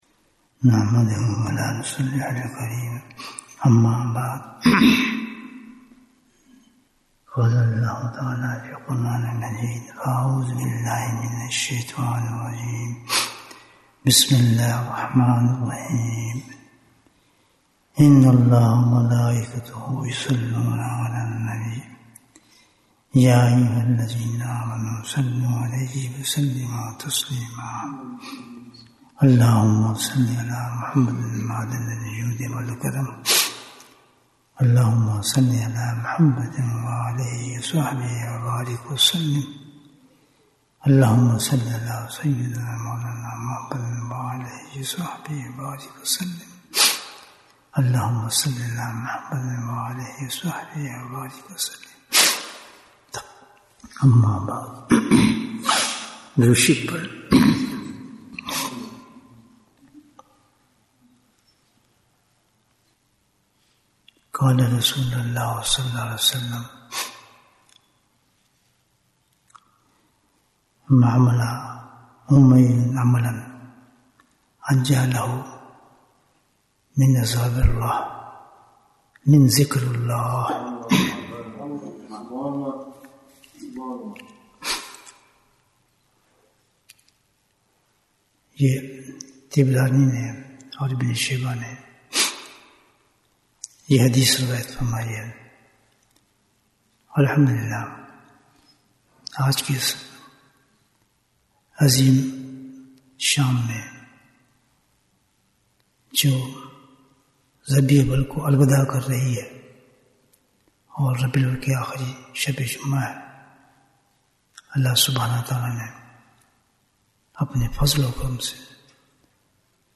Bayan, 67 minutes3rd October, 2024